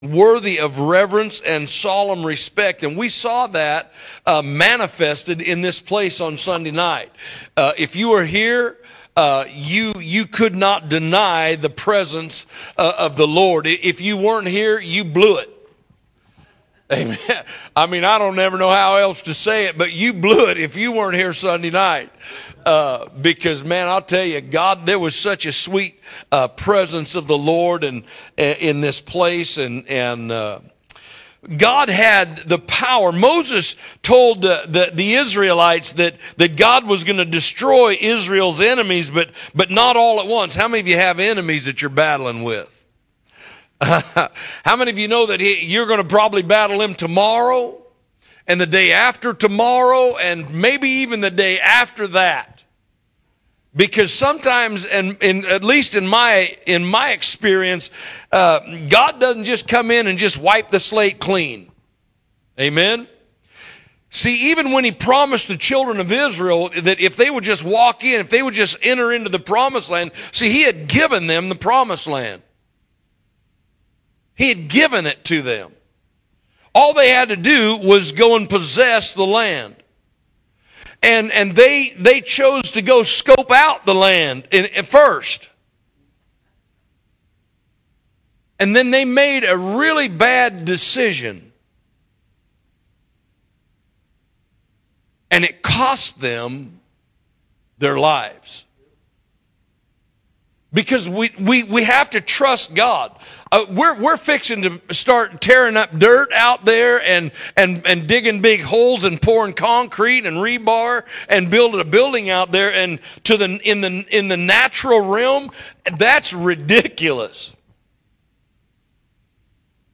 Midweek Service May 15, 2024 – Discipleship Training Part 26
Category: Teachings